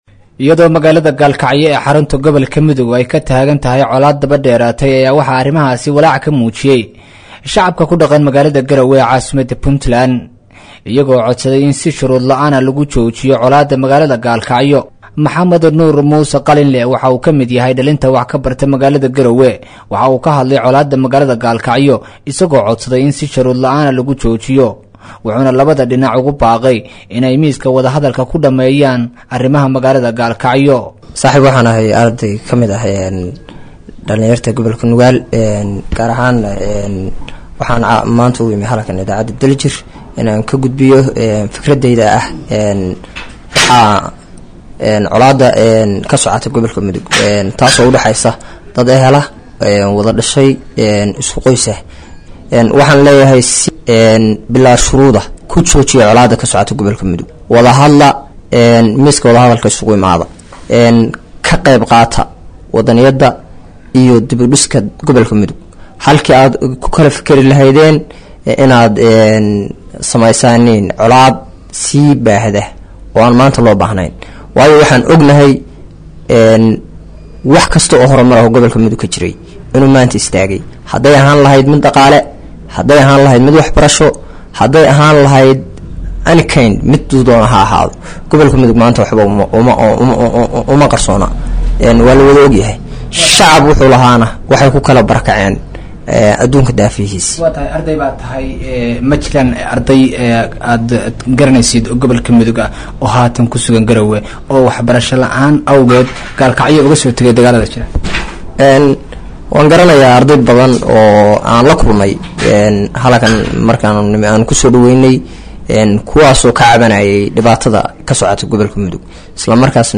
Qaar kamid ah dadka shacabka ah oo lahadlayey Idaacadda Daljir ayaa waxa ay ugu baaqeen dhinacyada ku dagaalamaya magaalada Galkacyo in lajoojiyo dagaalka loona hogaansamo xabad joojintii dhowaan lagu dhawaaqay.